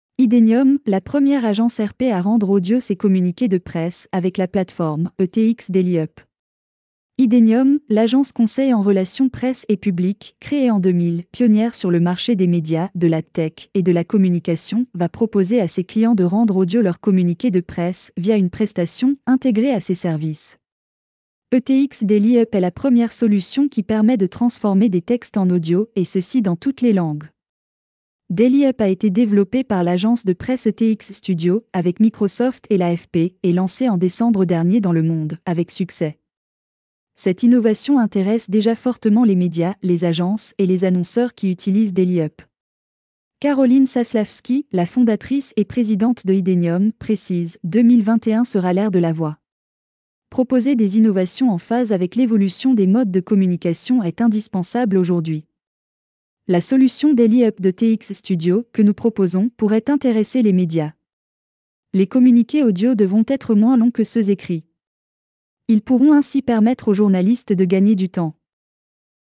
ETX Daily Up est une solution qui permet de transformer des textes en audio et ceci dans toutes les langues.